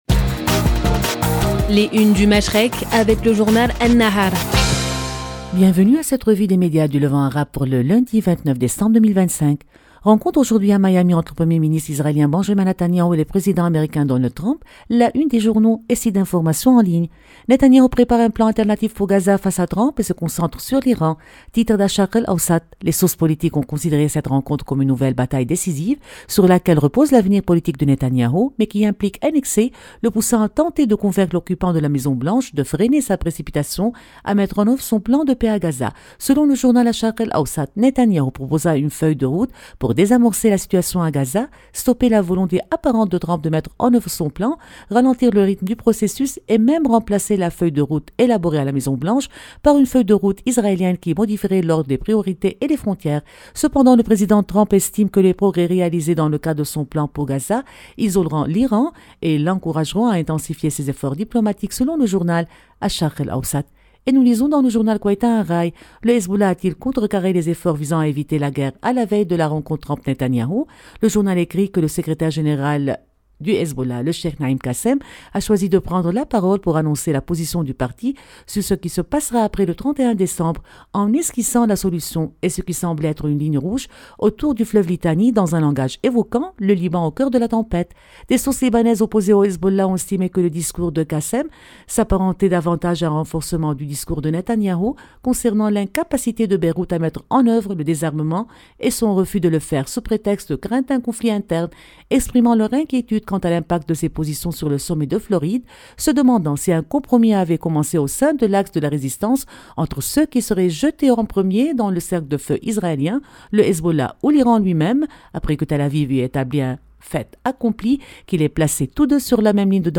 Revue de presse des médias arabes